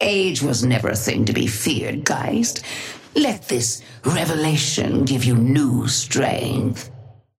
Sapphire Flame voice line - Age was never a thing to be feared, Geist. Let this revelation give you new strength.
Patron_female_ally_ghost_oathkeeper_5i_start_04.mp3